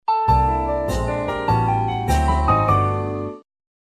Index of /phonetones/unzipped/LG/KU5500/Message tones
Piano.mp3